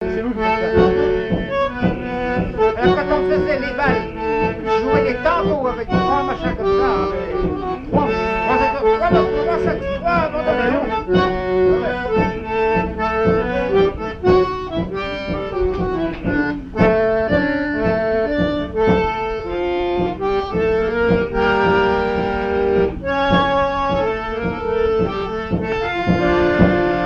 danse : tango
Vie de l'orchestre et son répertoire, danses des années 1950
Pièce musicale inédite